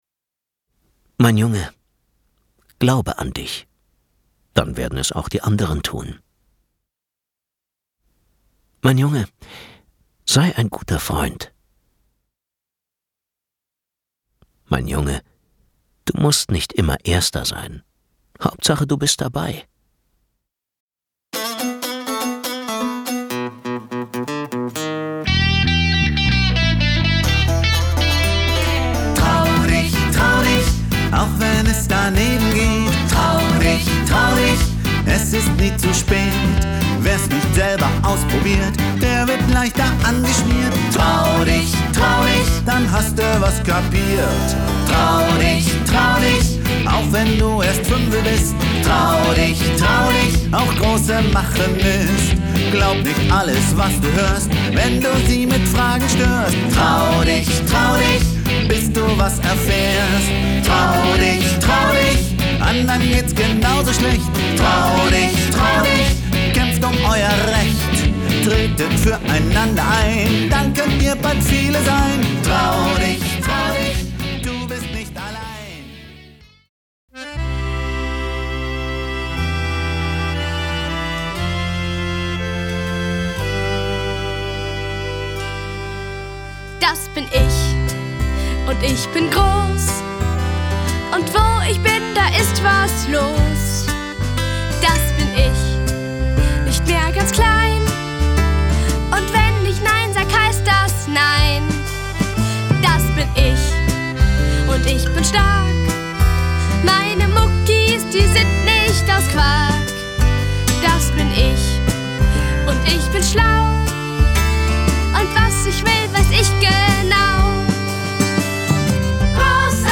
Unterstützt wird diese Geschichte von Kinderliedern, atmosphä-rischen Klängen und spielerischen Szenen passend zu den jeweiligen Stationen im Leben eines Jungen.